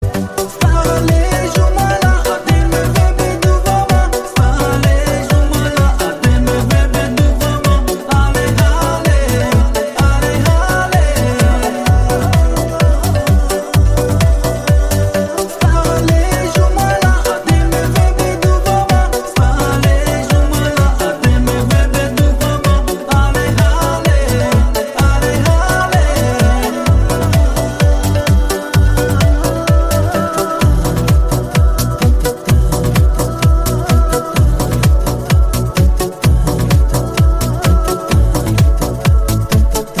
• Качество: 128, Stereo
мужской голос
арабские